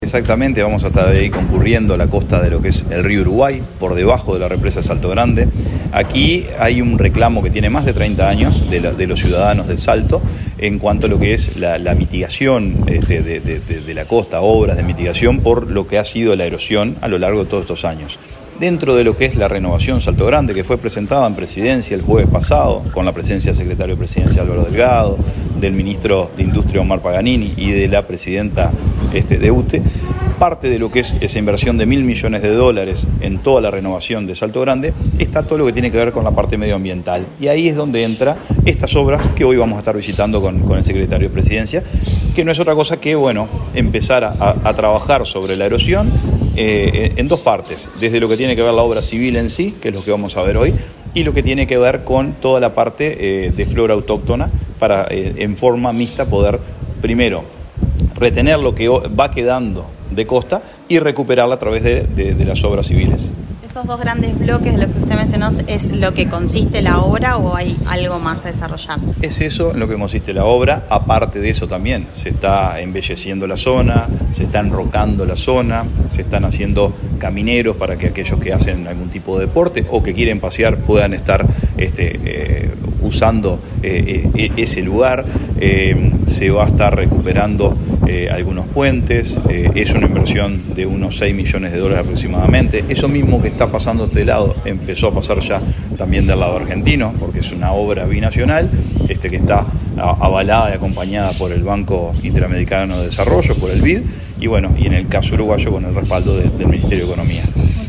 Entrevista al presidente de la Comisión Técnica Mixta de Salto Grande, Carlos Albisu